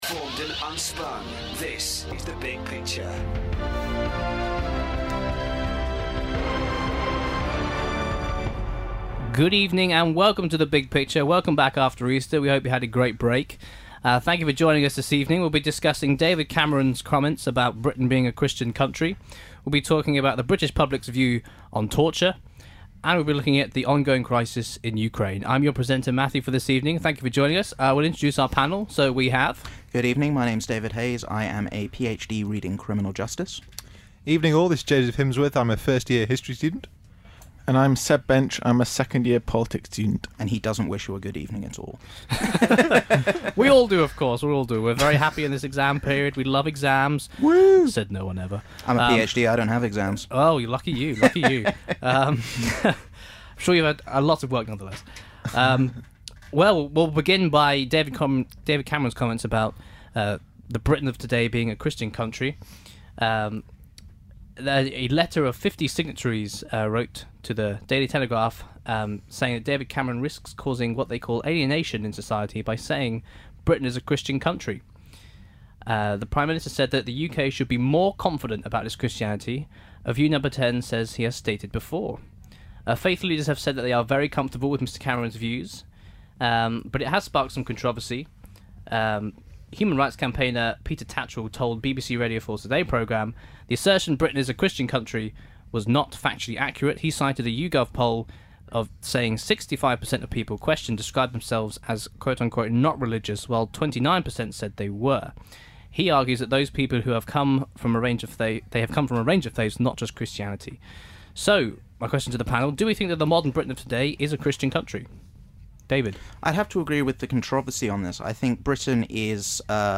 discussing the big news stories of the week